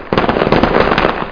knaller3.mp3